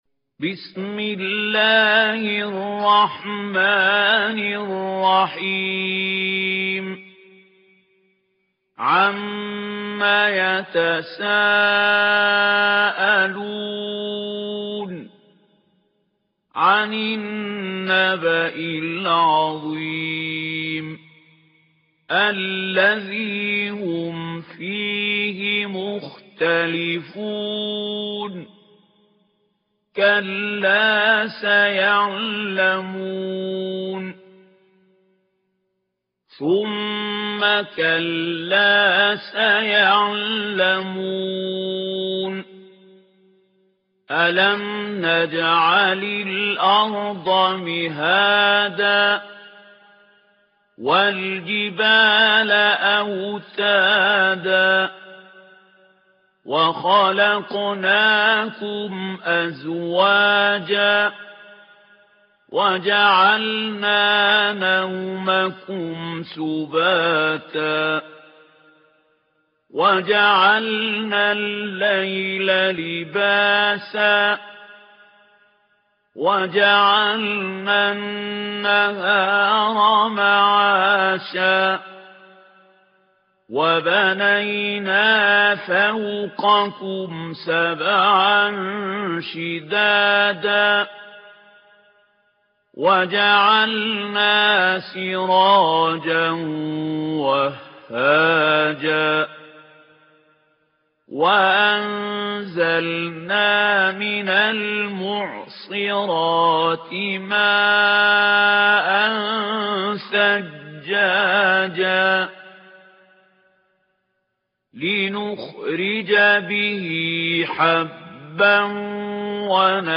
Enregistrements en studio
Genre : Tartîl dans le lectionnaire de Hafs.